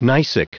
Prononciation du mot gneissic en anglais (fichier audio)
Prononciation du mot : gneissic